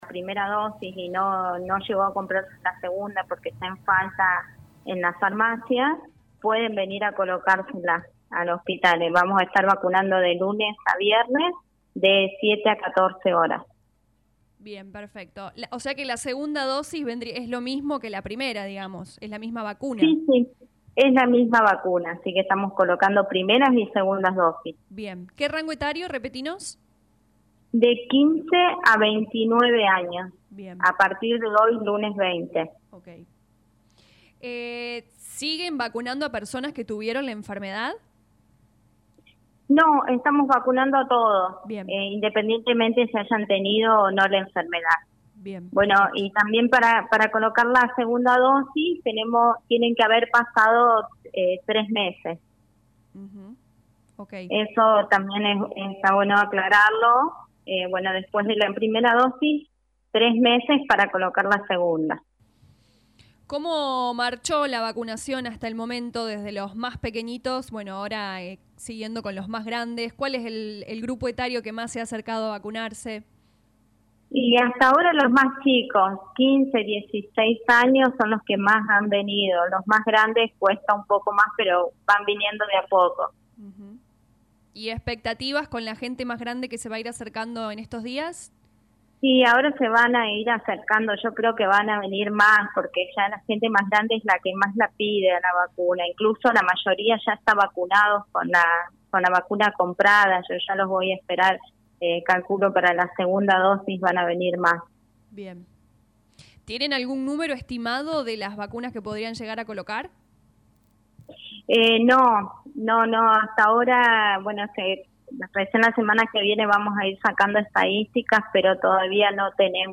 Lo confirmó en diálogo con LA RADIO 102.9 FM